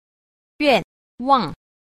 8. 願望 – yuànwàng – nguyện vọng